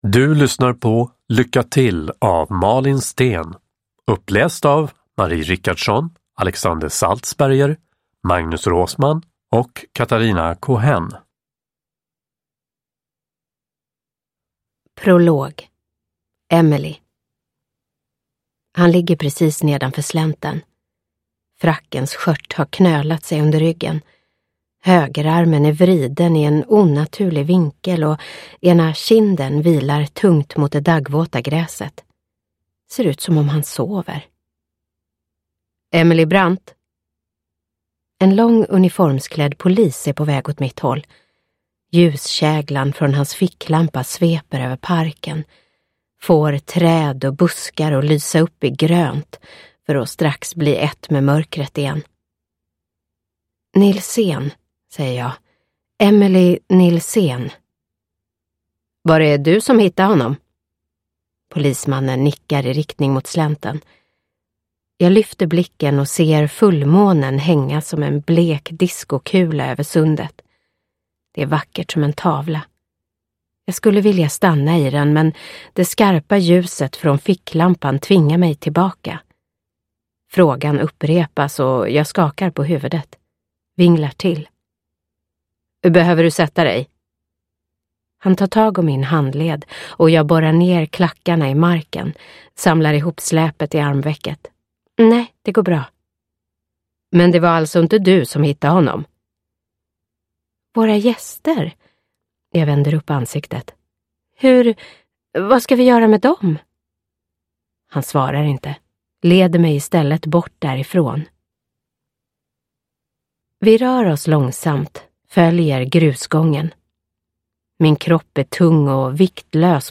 Lycka till – Ljudbok – Laddas ner